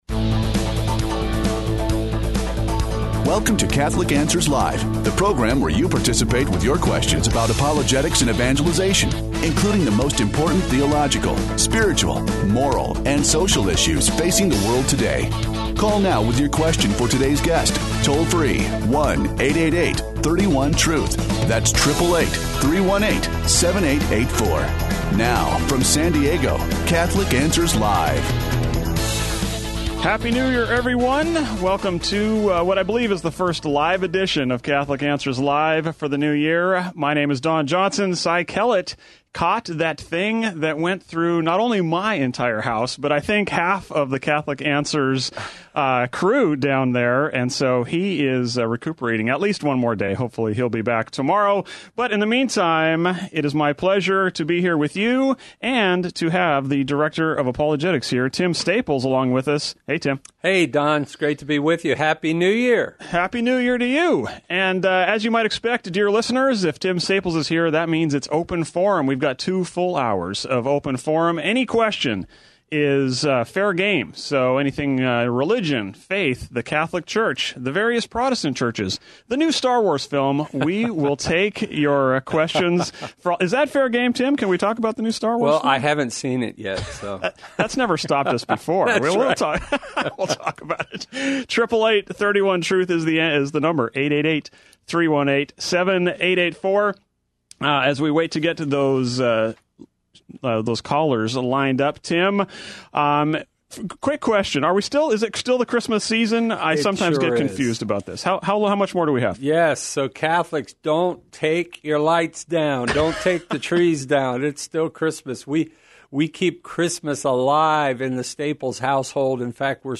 The callers choose the topics during Open Forum, peppering our guests with questions on every aspect of Catholic life and faith, the moral life, and even philos...